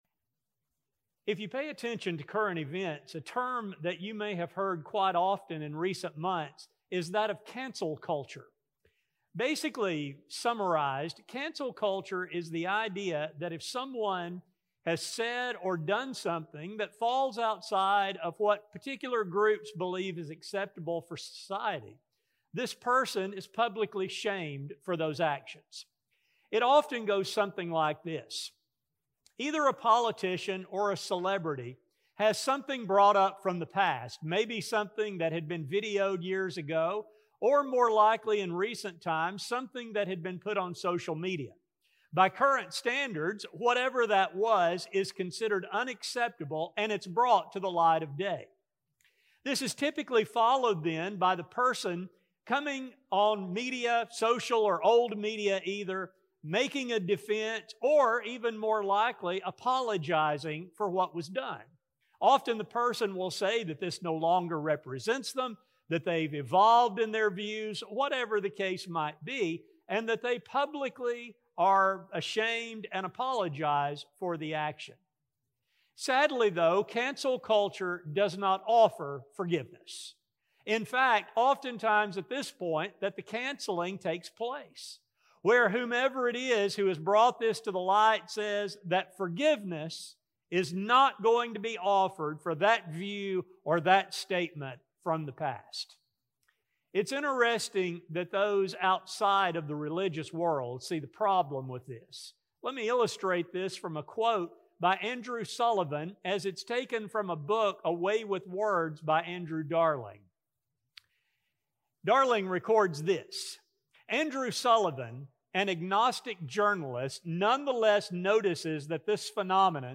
While "Cancel Culture" is a popular term, it is a very old idea that has no place for the child of God. In this study, we explore our attitudes toward forgiveness to remember that current trends must be avoided. A sermon recording